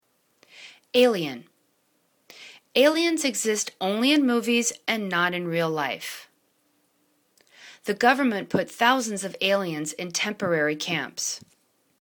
a.li.en     /'a:liən/    n